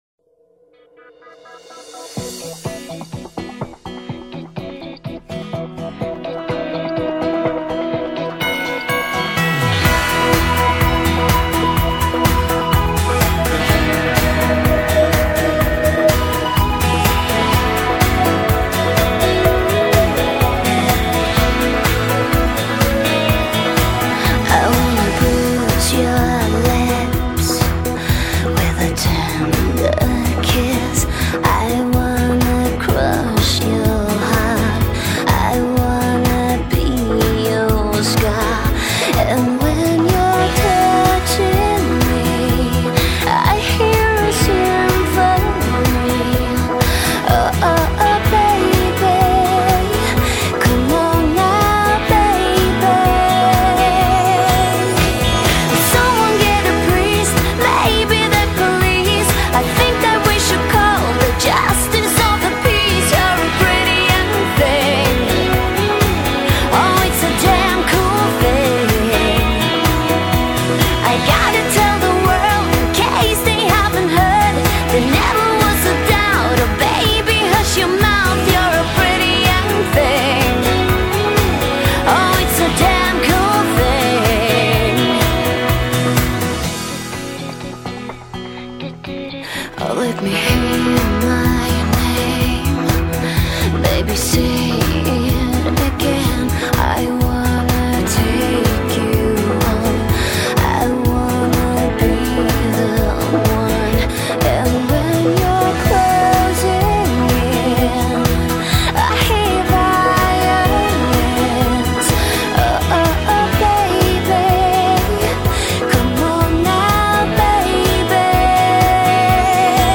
被人称为北国精灵的挪威年轻女歌手，轻快的节奏很快的把人的情绪给吸引。